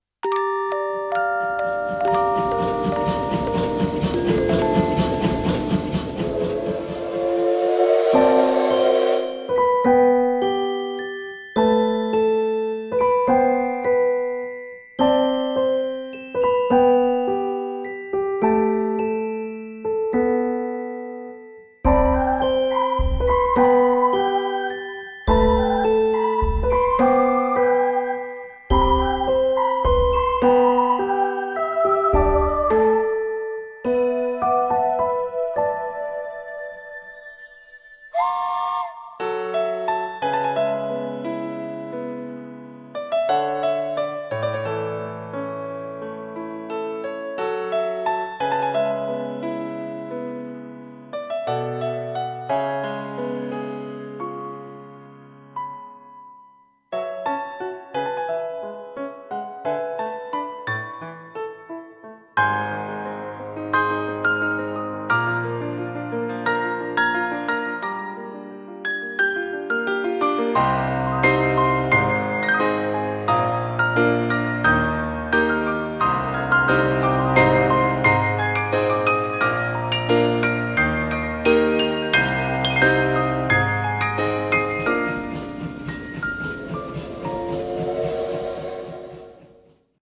CM風声劇「星渡り鉄道